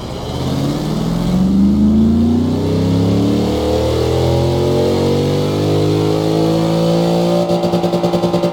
Index of /server/sound/vehicles/lwcars/chev_suburban
rev.wav